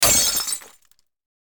Download Glass Breaking sound effect for free.
Glass Breaking